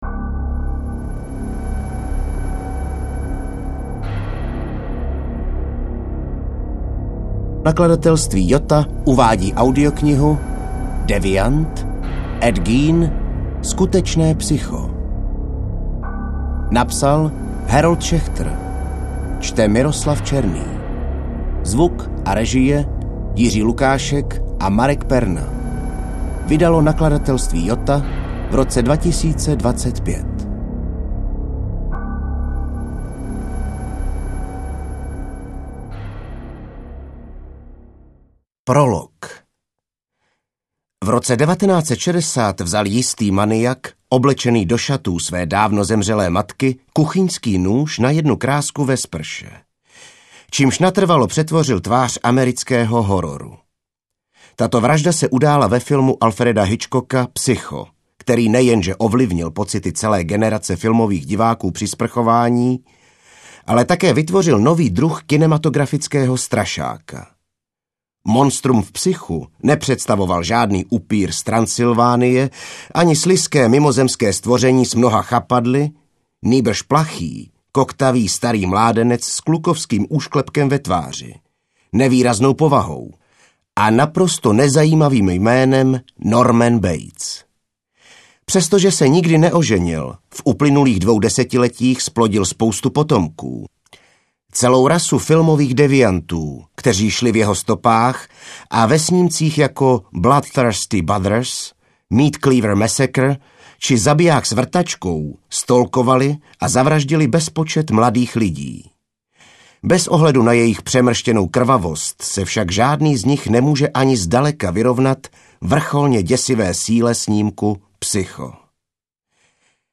AudioKniha ke stažení, 45 x mp3, délka 9 hod. 27 min., velikost 516,9 MB, česky